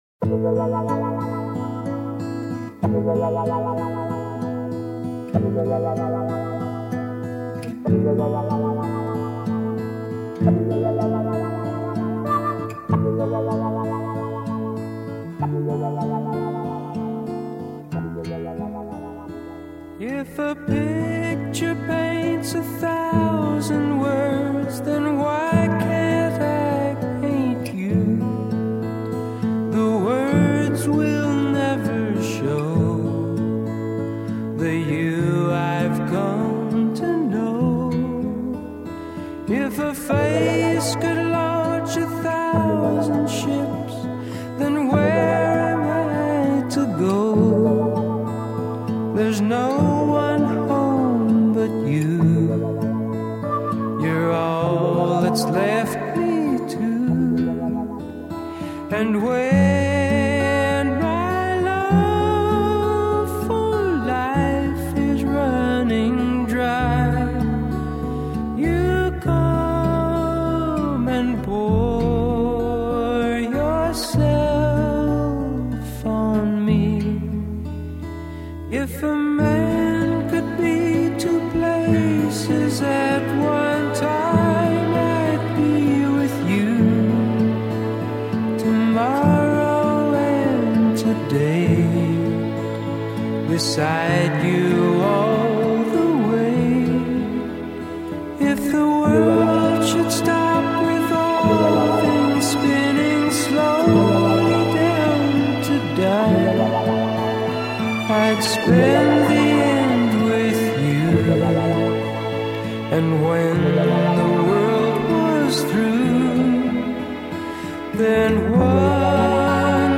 американской поп-рок группы